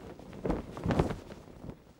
cloth_sail4.L.wav